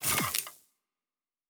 Weapon 14 Reload 2 (Flamethrower).wav